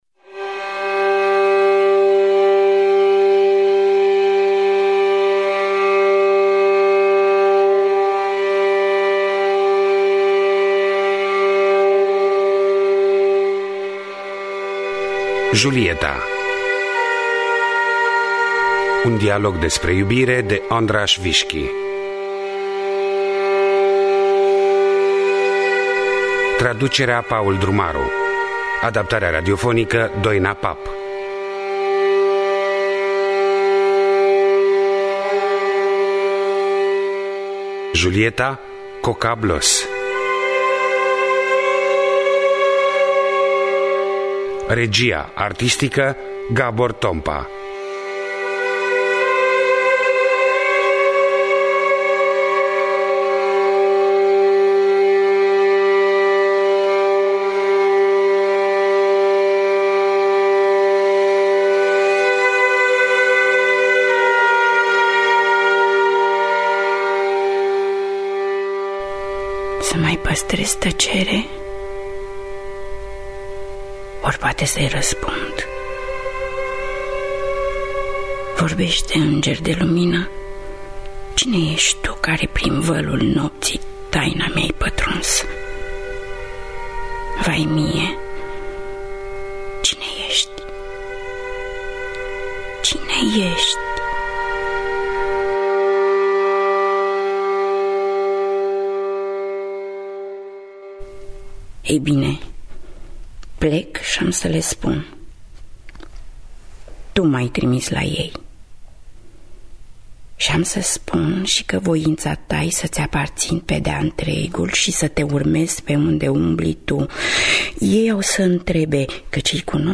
Adaptarea radiofonică
În rolul titular: Coca Bloos.